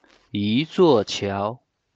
一座桥/Yīzuò qiáo/Un puente